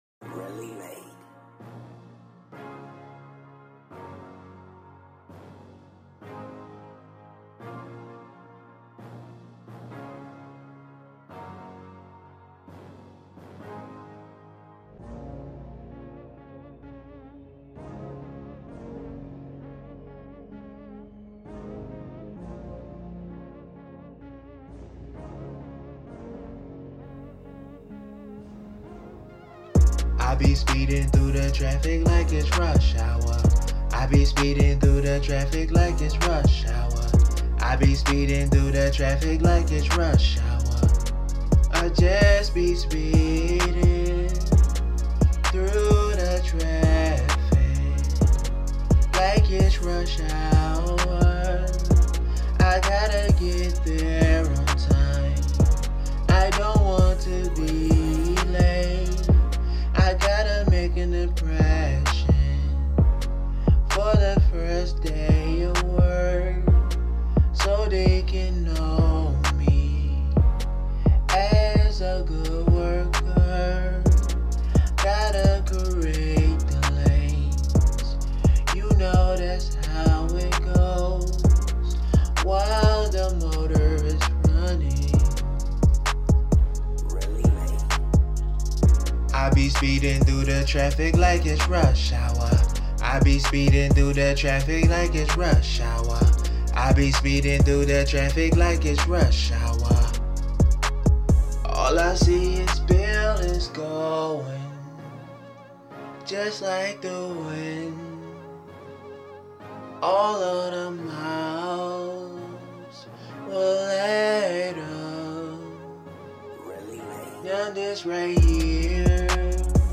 Hiphop
Rushing Traffic Action